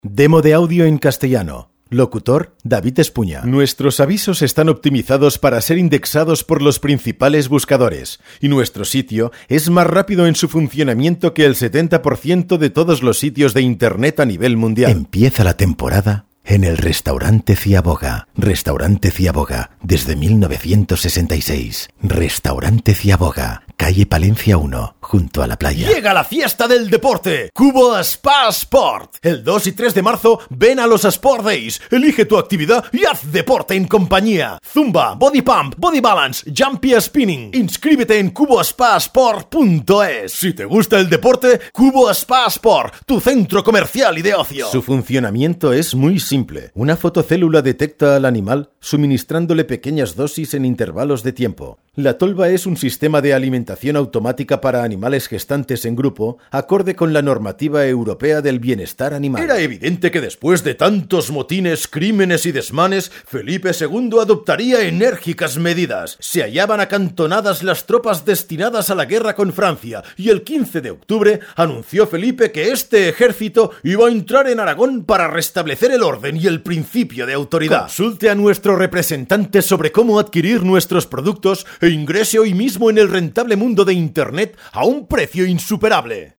The warm timbre of my voice brings serious suitability for any type of audio, which accompanied a custom job for each project meets the requirements of the script.
Kein Dialekt
Sprechprobe: Werbung (Muttersprache):
Experienced VoiceOver. Warm and deeply timbre.